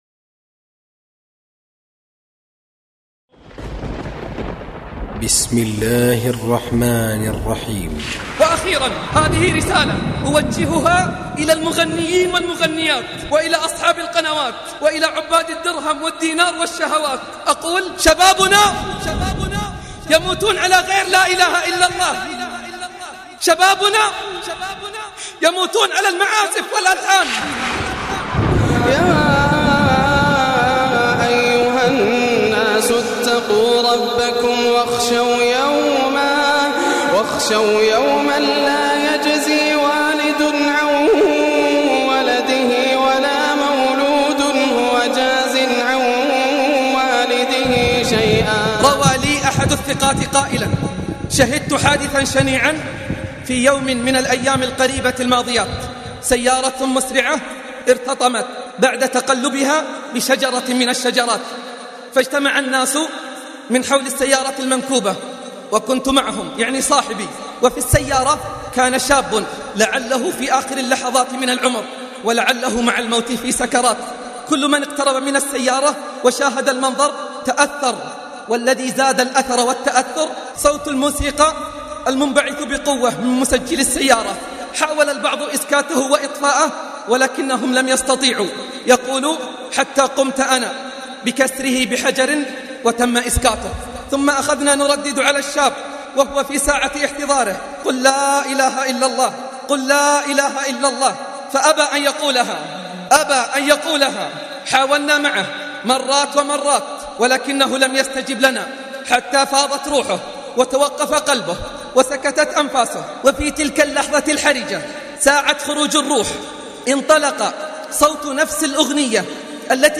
أحب الأغاني ـ تلاوة إدريس أبكر - قسم أغســــل قلــــــبك1